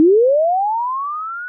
Free UI/UX sound effect: Level Up.
031_level_up.mp3